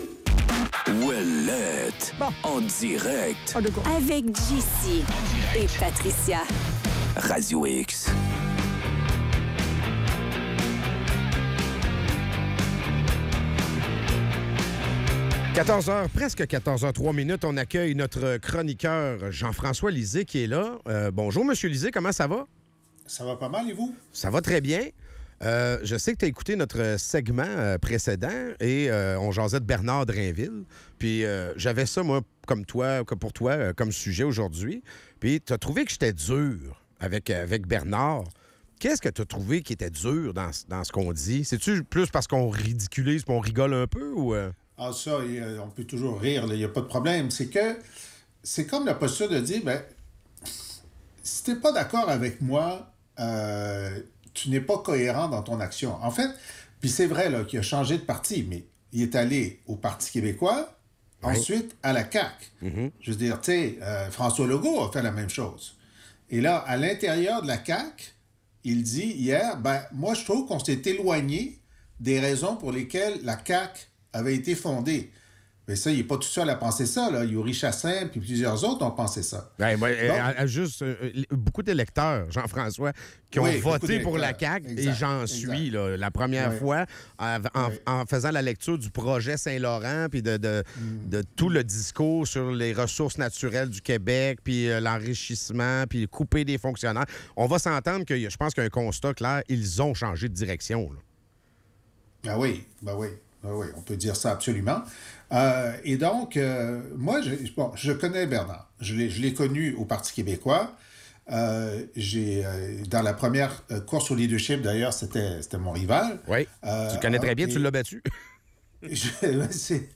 Bernard Drainville est au cœur des discussions, avec Jean-François Lisée qui souligne son opportunisme politique entre les partis et ses changements de cap idéologiques. Les animateurs analysent la direction actuelle de la CAQ, qui semble revenir à une approche plus conservatrice, tout en abordant les enjeux de réduction de la taille de l'État et de baisse des impôts pour les PME.